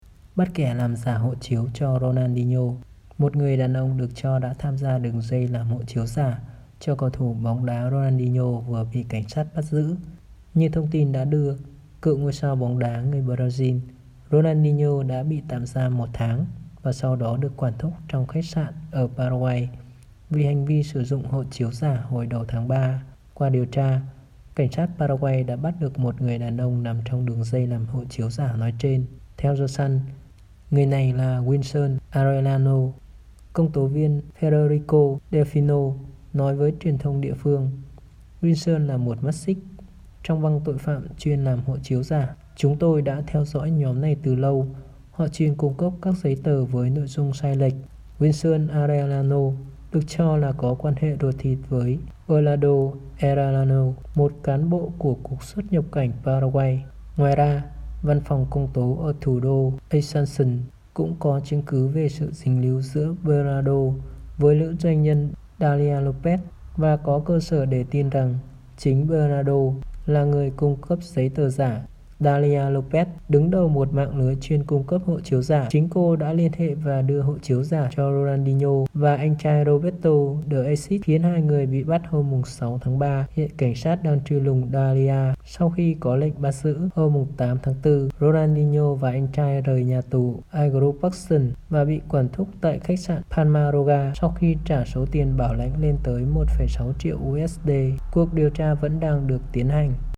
Bản tin audio